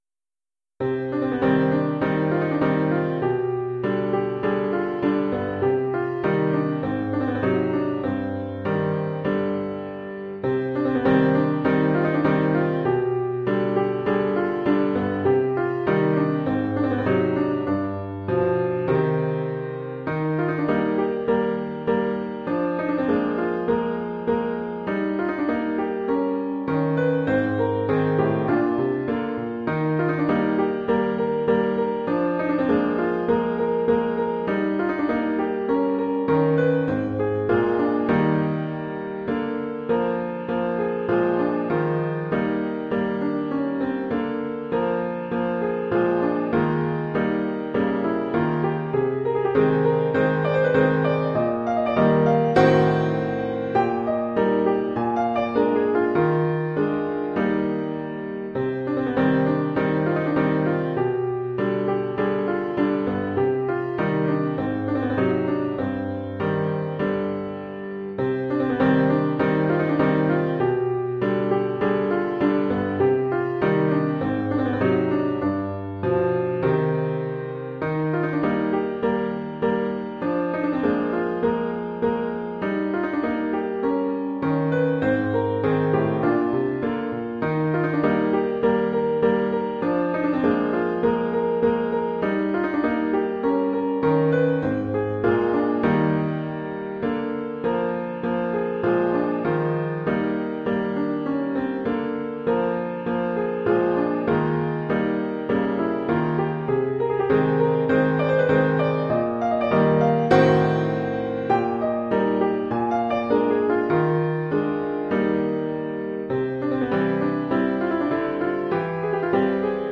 Instrumentation : Piano
Oeuvre pour piano solo.